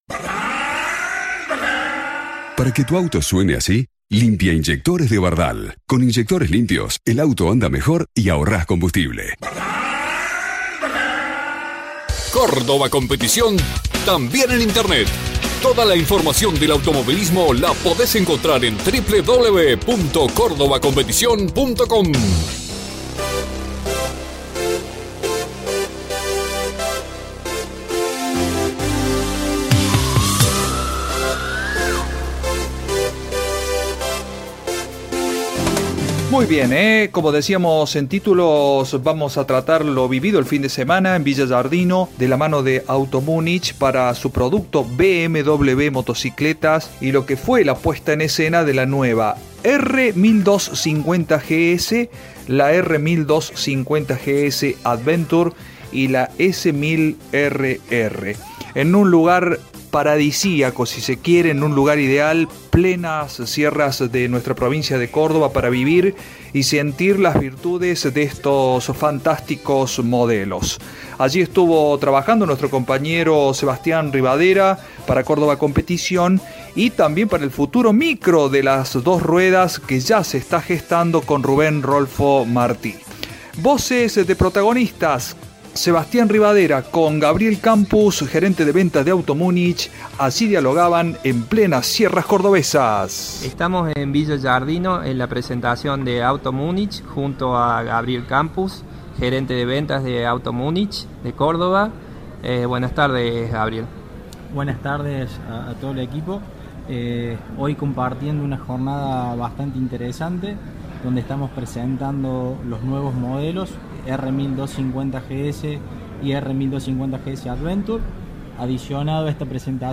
De este modo reflejábamos en el aire de radio Continental Córdoba lo acontecido en «El Jardín de Punilla» con Auto Munich y el lanzamiento de las nuevas BMW: